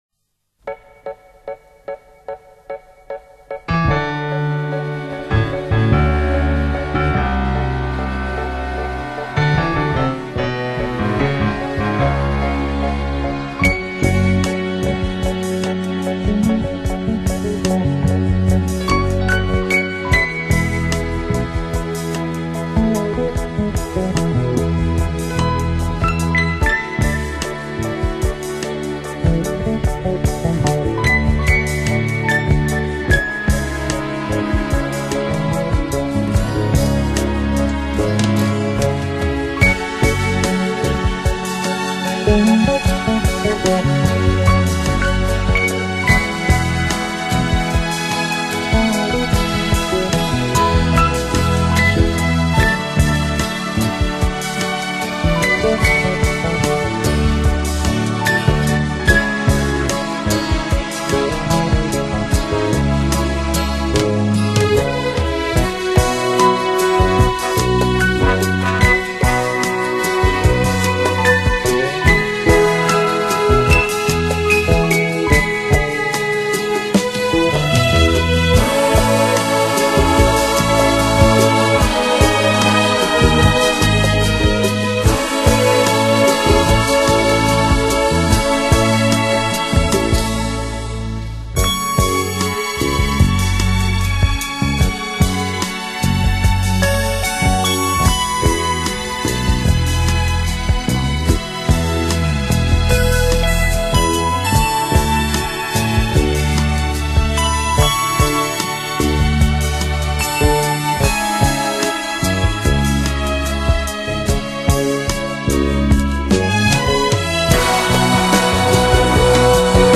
世界著名三大轻音乐团之一，法国音乐之神，世界音乐史上的瑰宝，
他的音乐能让您在美妙的乐声中重温美丽的往日记忆，畅游华丽浪漫的情调音乐时空，